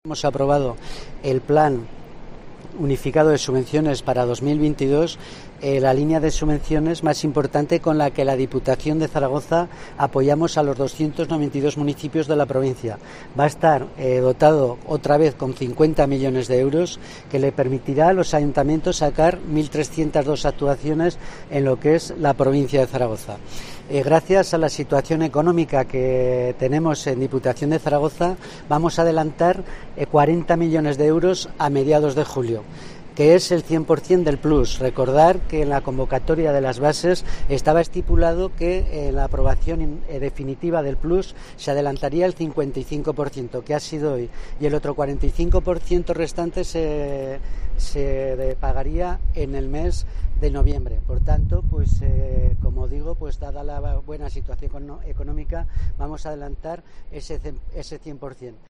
El presidente de la DPZ, Juan Antonio Sánchez Quero, explica los detalles del PLUS 2022 aprobado por el pleno.